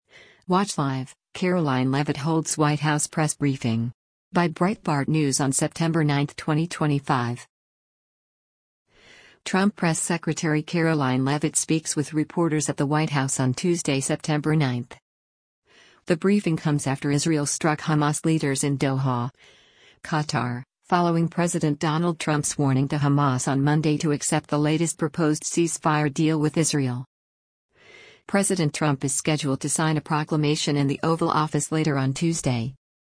Trump Press Secretary Karoline Leavitt speaks with reporters at the White House on Tuesday, September 9.